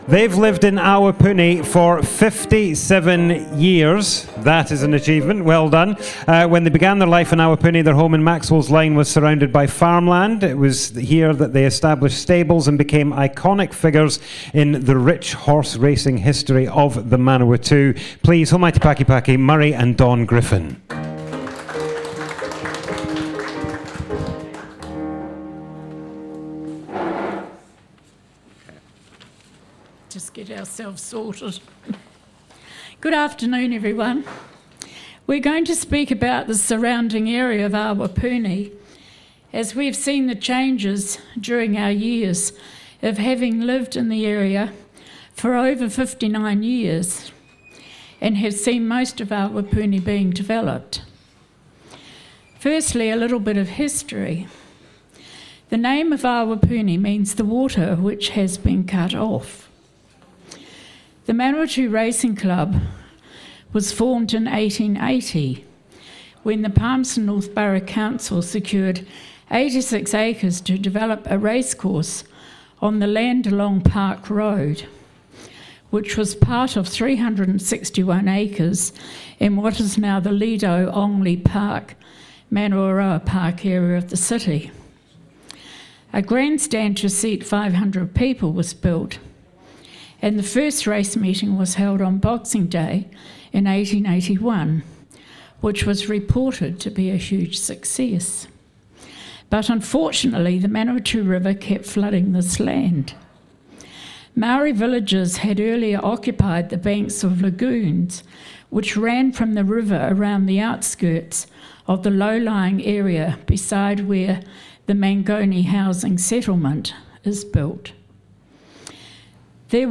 This event was part of Local History Week 2021.